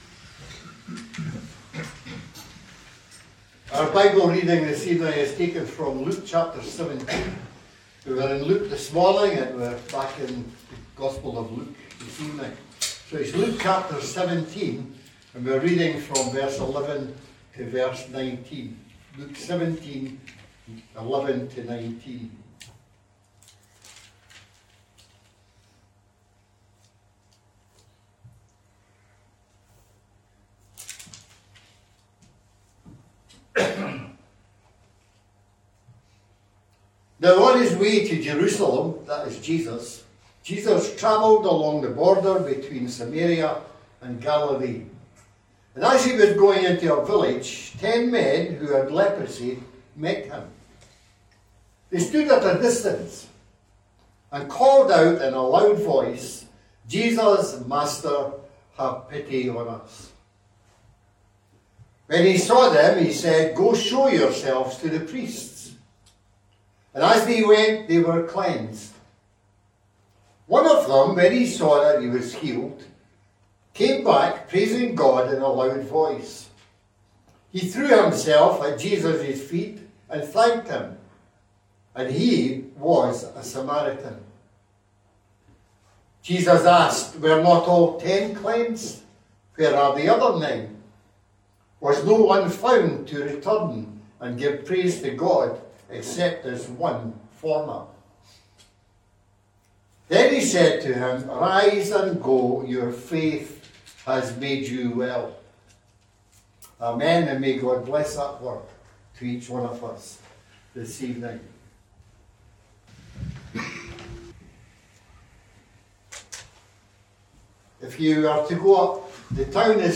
A link to the video recording of the 6:00pm service, and an audio recording of the sermon.